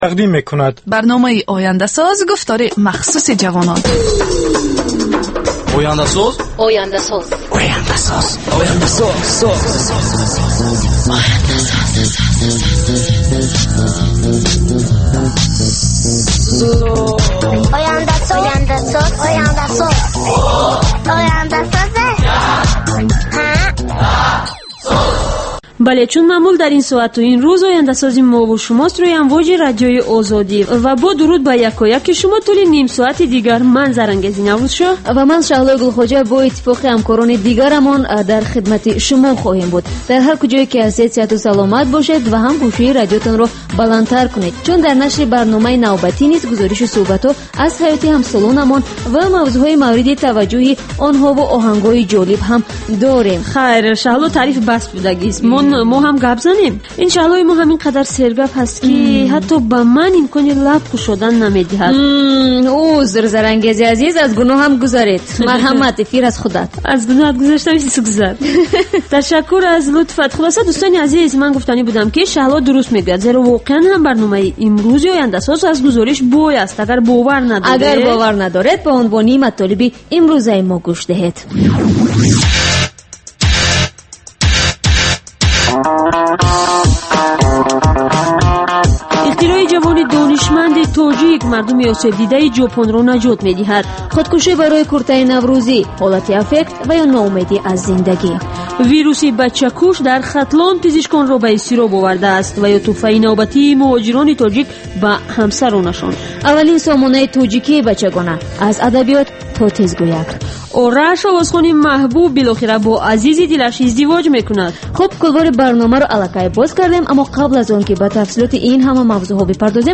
Бар илова, дар ин гуфтор таронаҳои ҷаззоб ва мусоҳибаҳои ҳунармандон тақдим мешавад.